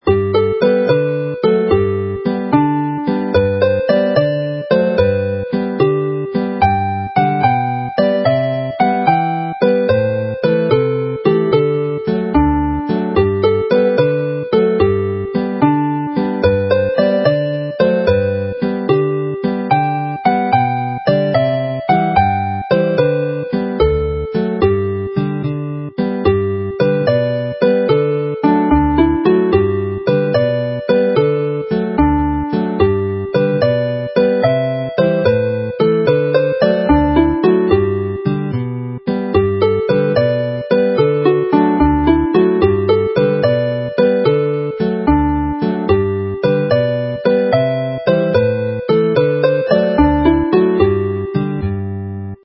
a lively formal Welsh dance
Chwarae'r alaw'n araf